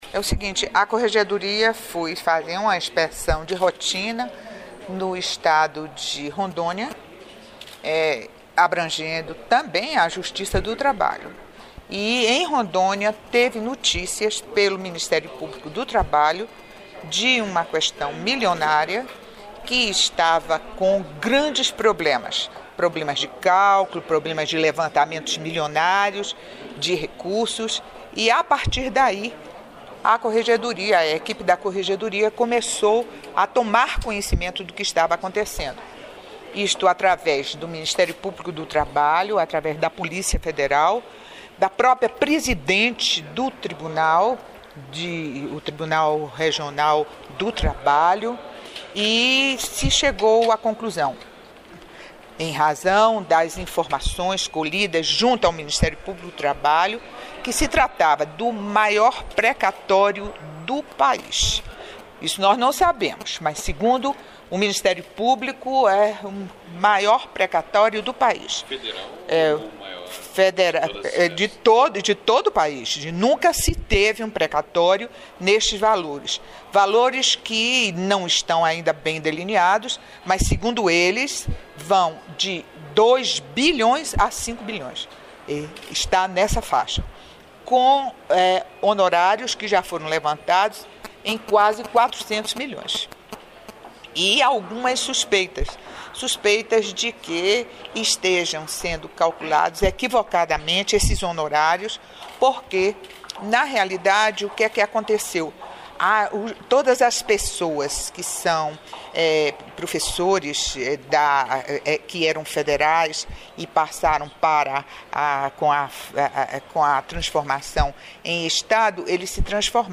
Ouça a entrevista com a Ministra Eliana Calmon.